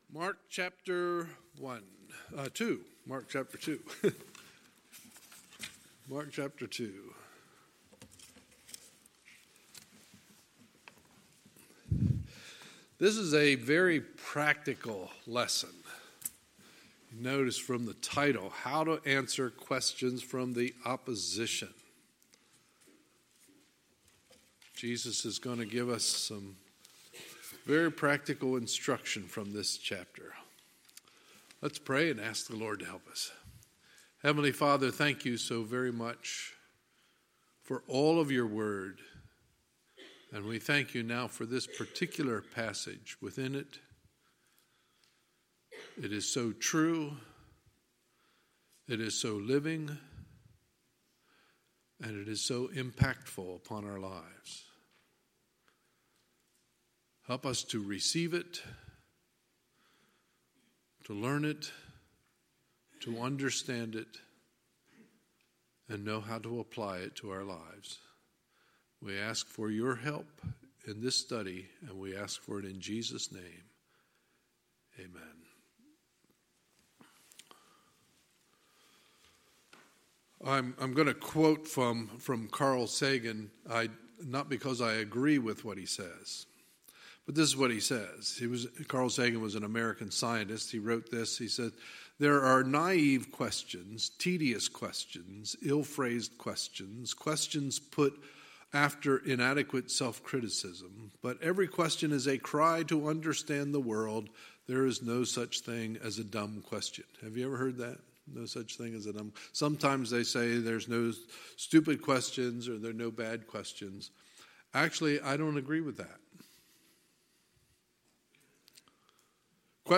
Sunday, February 17, 2019 – Sunday Morning Service